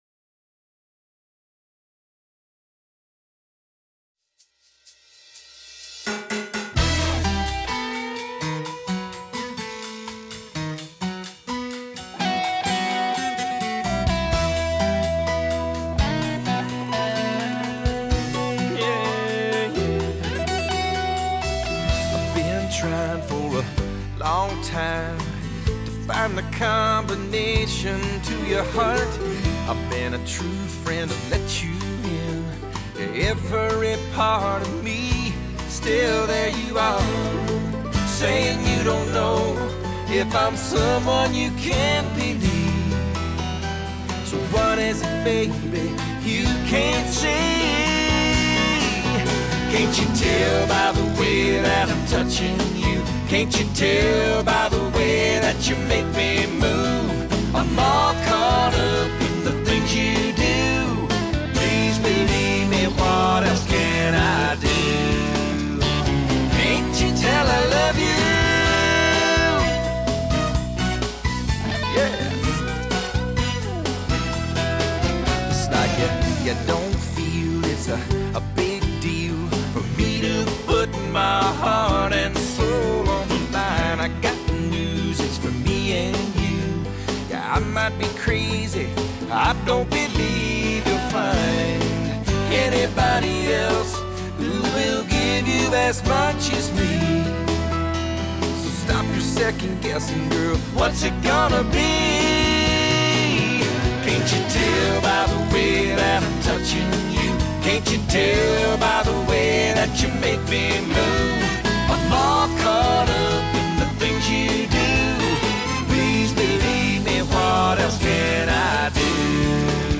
В основном это всё радио-версии.